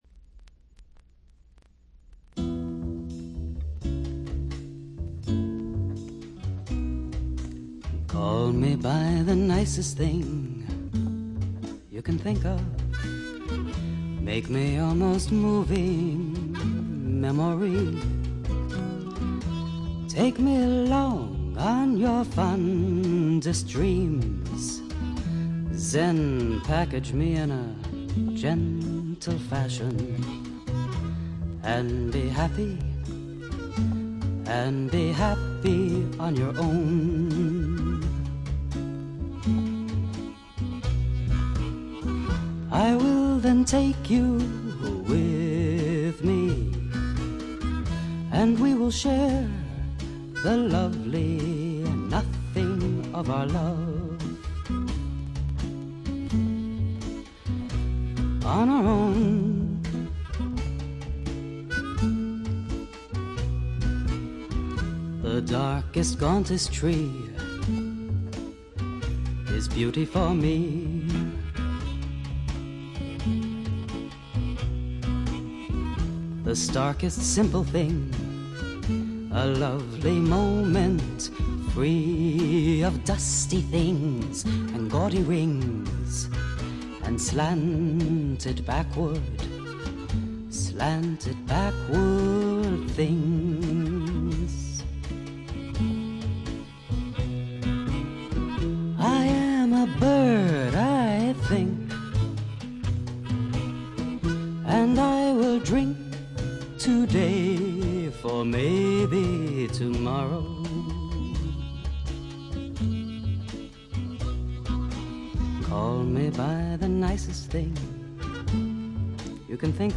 60年代気分満開の中に漂うダークでブルージーなアシッドな香りがまた良いです。
試聴曲は現品からの取り込み音源です。